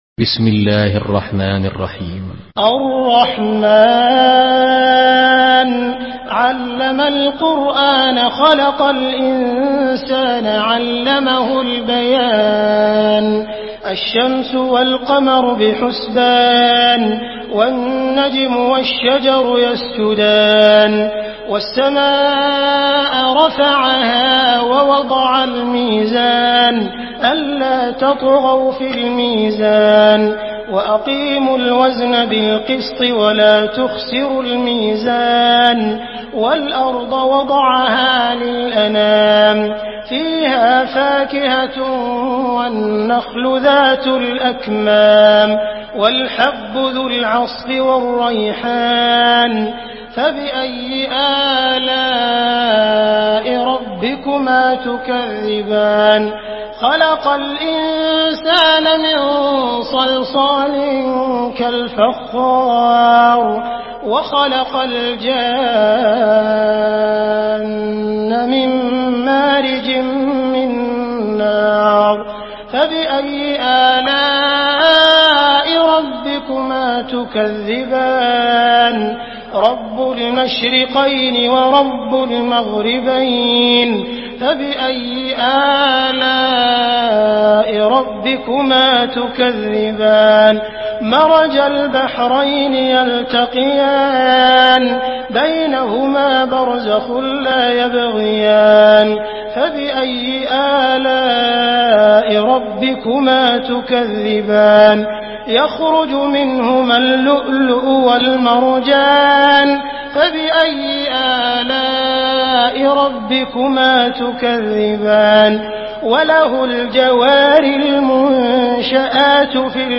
Surah الرحمن MP3 in the Voice of عبد الرحمن السديس in حفص Narration
مرتل حفص عن عاصم